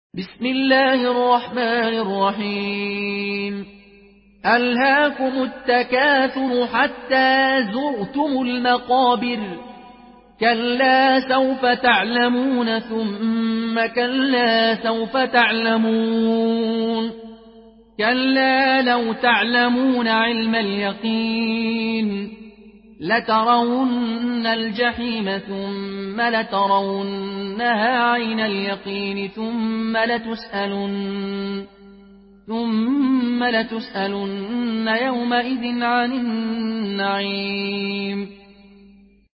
Murattal Qaloon An Nafi